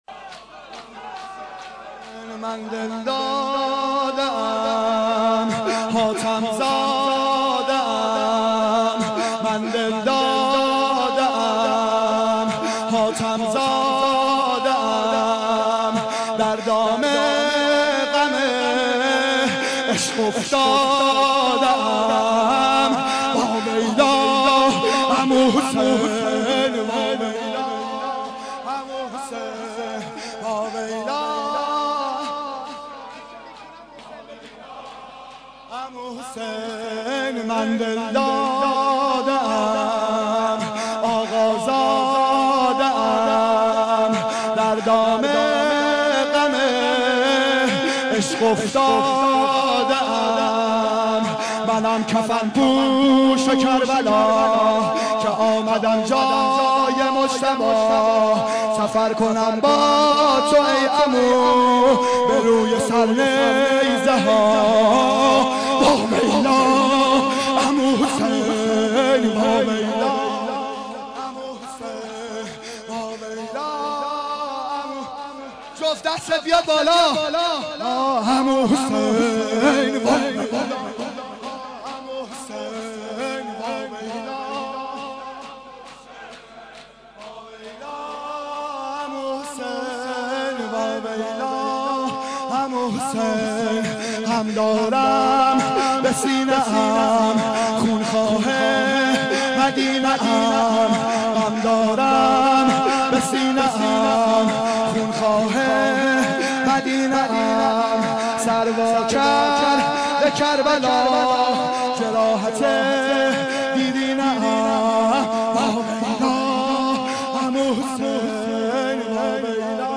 شور شب ششم محرم1391